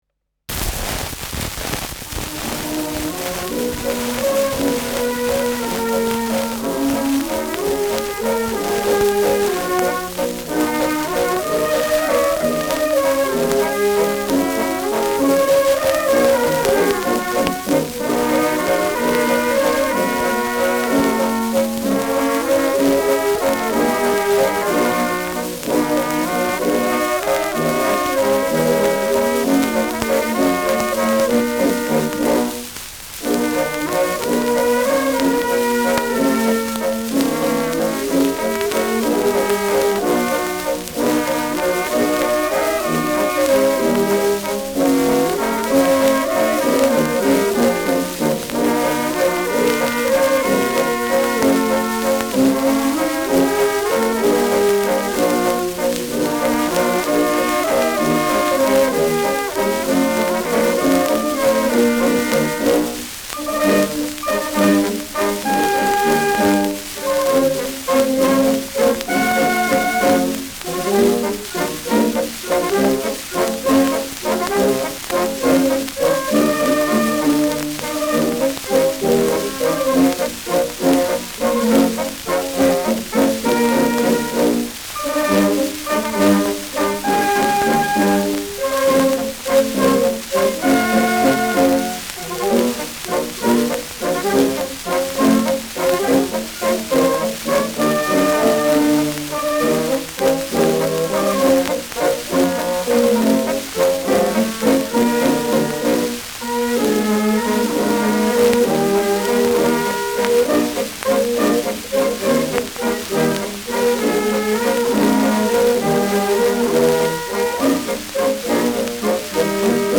Schellackplatte
[Nürnberg] (Aufnahmeort)